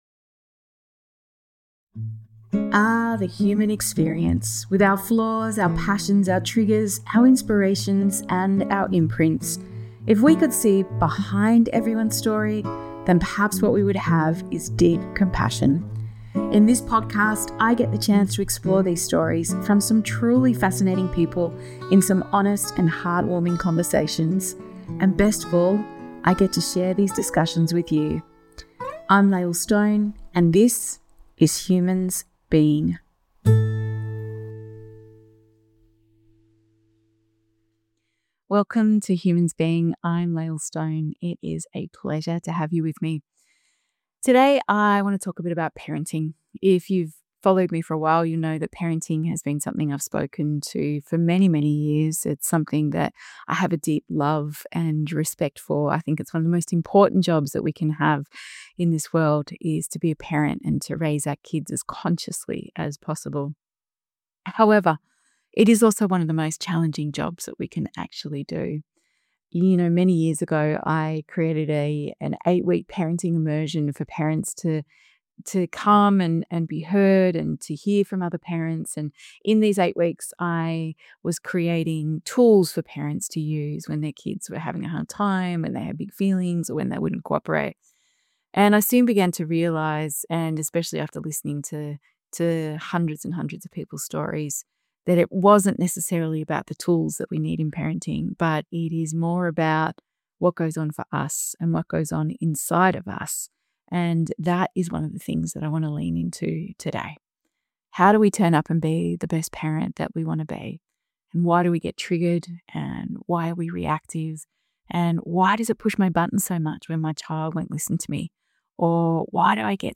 In this solo episode, I wanted to lean into something that I've been exploring for nearly 20 years – why parenting feels so hard, and why we react the way we do.